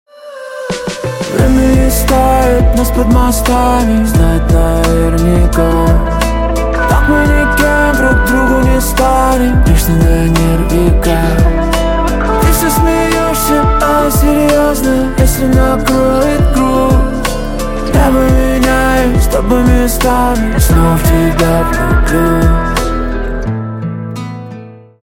РИНГТОН
Поп